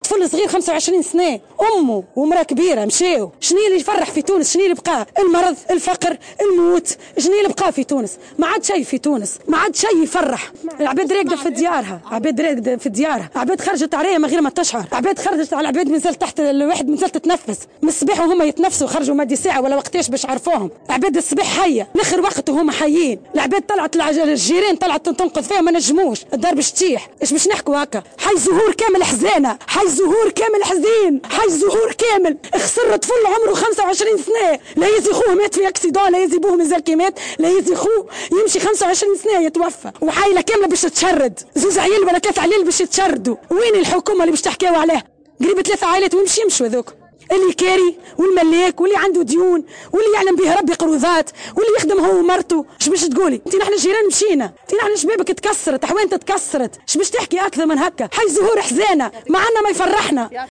حادثة انفجار حي الزهور: شاهدة عيان تروي تفاصيل مؤلمة